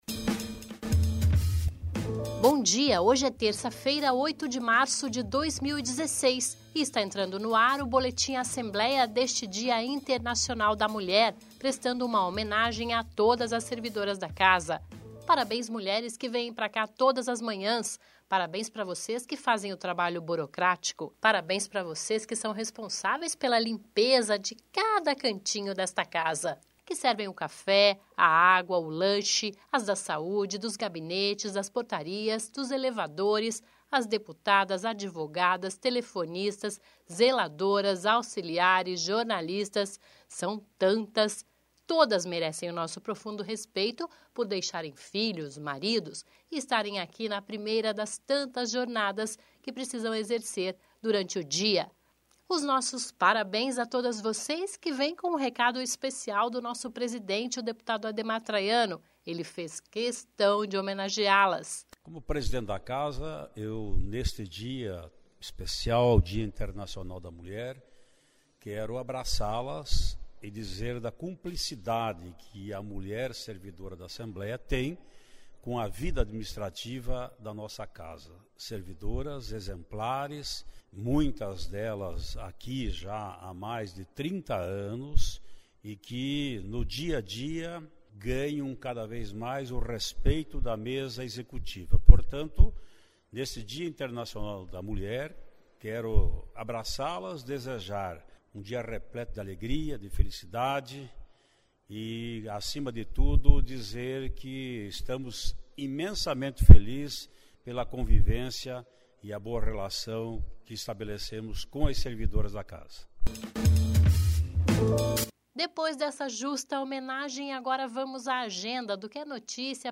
Ouça o Boletim Assembleia com a mensagem do presidente Ademar Traiano às servidoras da Assembleia.